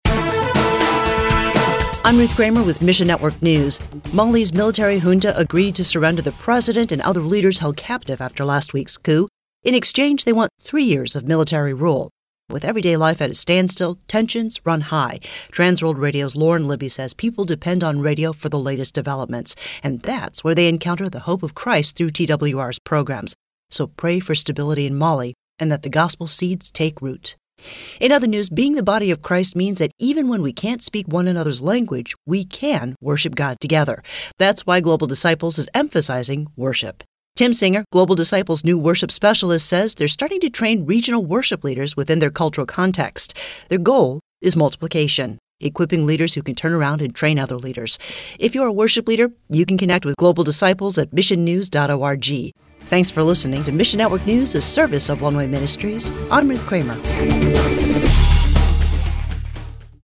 Audio Broadcast